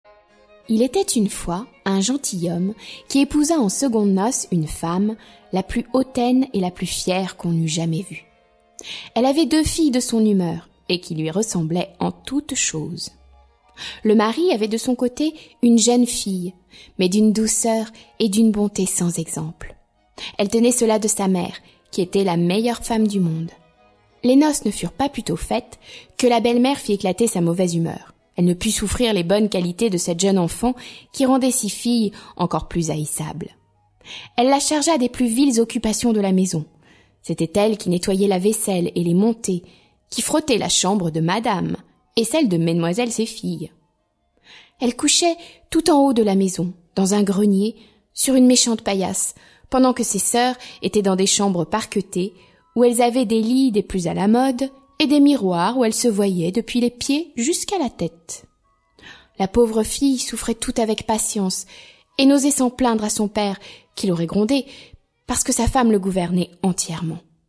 Conte de Charles Perrault Musique : Bach (4ème concerto brandebourgeois) et Debussy (Après midi du faune)